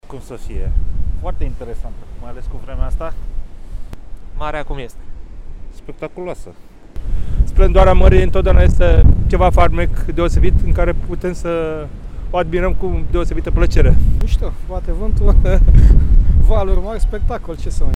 VIDEO: Furtună pe Marea Neagră. Imagini spectaculoase de pe faleza Cazinoului
YouTube: Cazinoul din Constanța în timpul furtunii
Oamenii au încercat să surprindă valurile care se spărgeau de țărm: